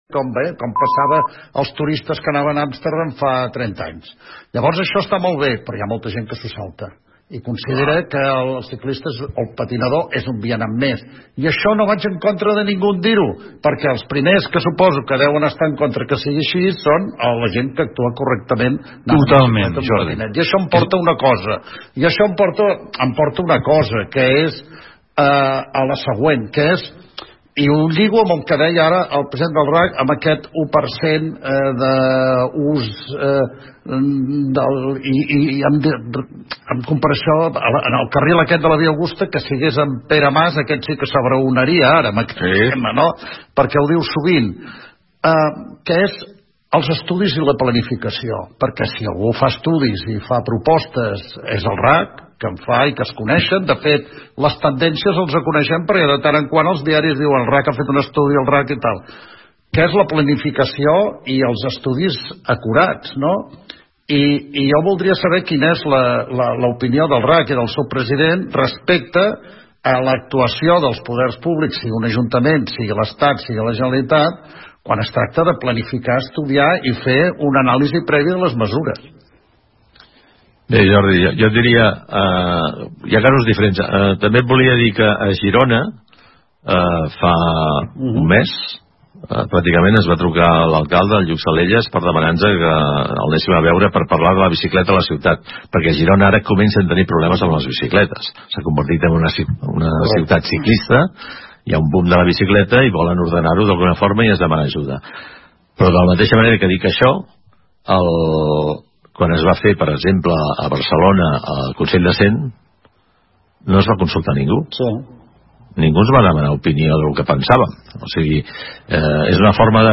"El món a RAC1" conmemora el 120 aniversario del RACC El viernes de 20 febrero, la sede del RACC en Barcelona acogió una emisión especial en directo del programa "El món a RAC1”, presentado por Jordi Basté.